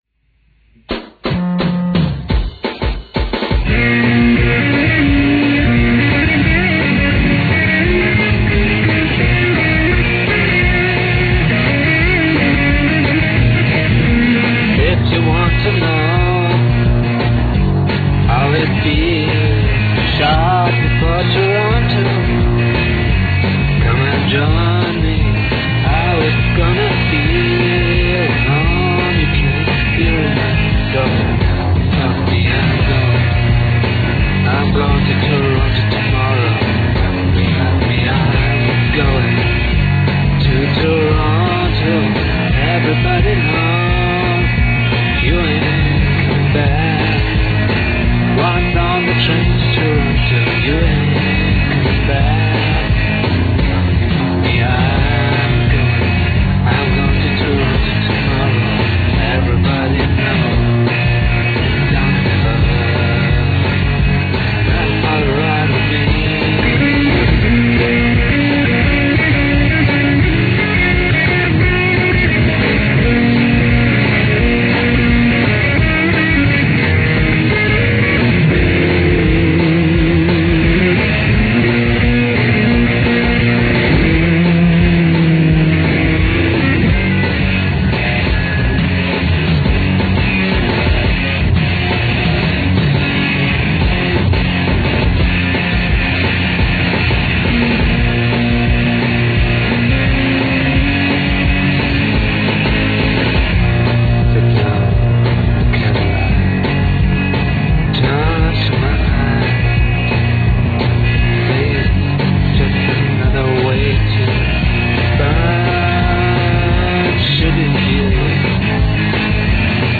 MONO, SOUND LEVEL B